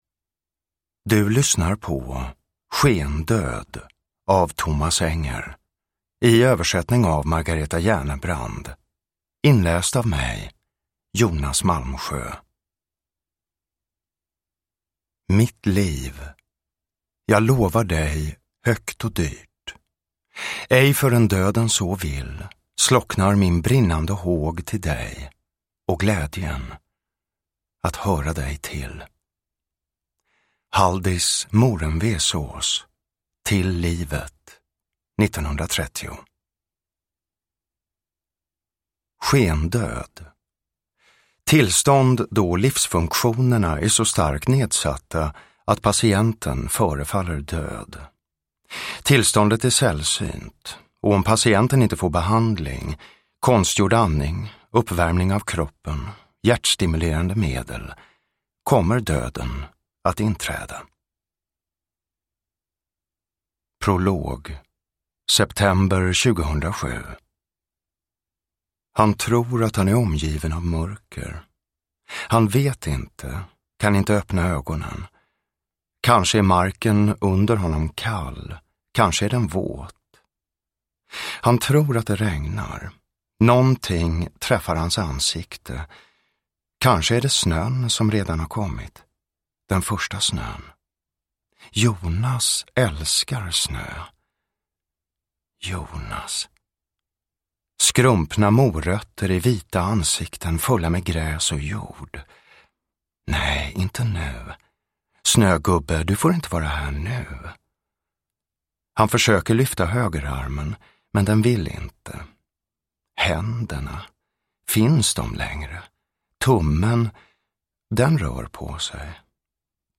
Skendöd – Ljudbok – Laddas ner
Uppläsare: Jonas Malmsjö